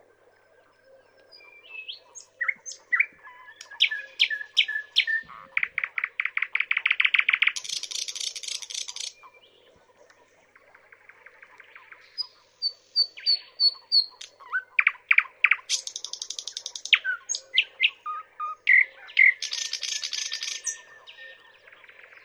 • thrush nightingale song.wav
thrush_nightingale_song-2_Xb7.wav